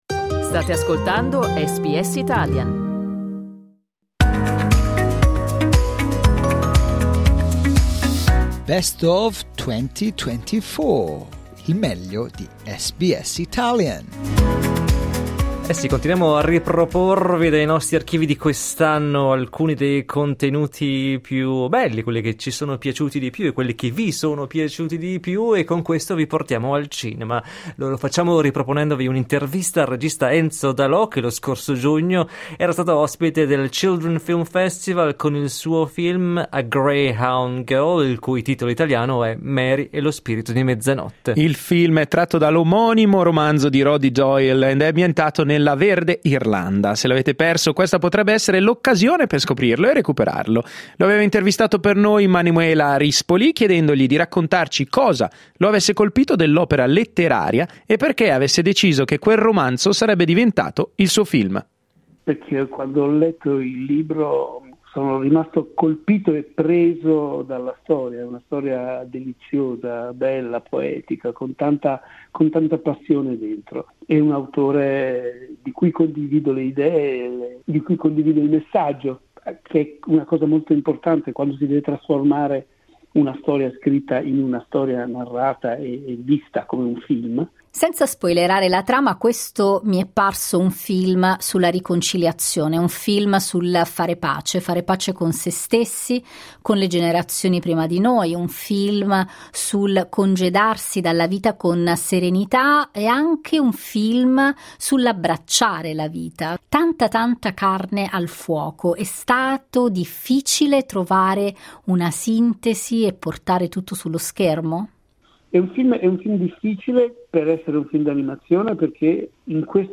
Dai nostri archivi, un'intervista con il regista Enzo D'Alò, in cui spiega che cosa lo ha spinto ad adattare allo schermo il romanzo Mary e lo spirito di mezzanotte ("A Greyhound of a Girl").